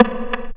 prcTTE44037tom.wav